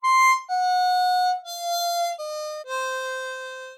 Low-end-on-C-Harp-adding-5OB-the-F-Flat5th.mp3